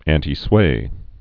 (ăntē-swā, ăntī-)